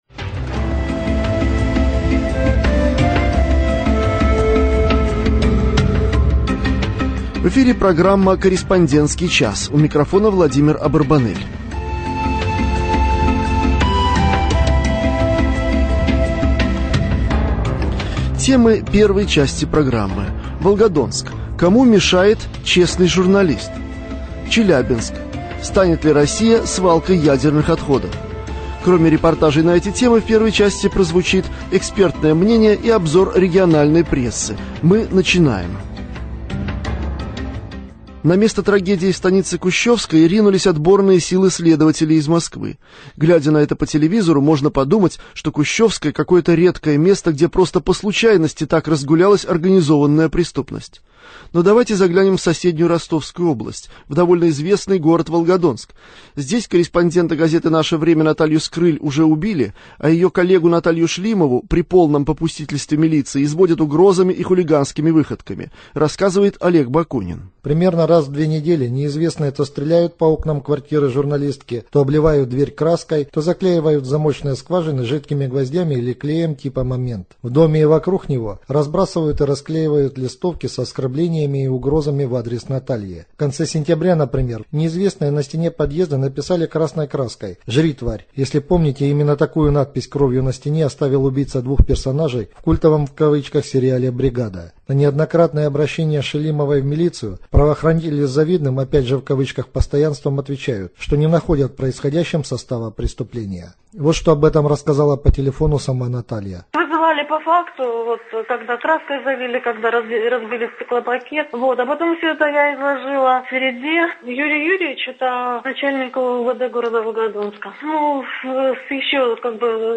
Еженедельная серия радиоочерков о жизни российской провинции. Авторы из всех областей России рассказывают о проблемах повседневной жизни обычных людей.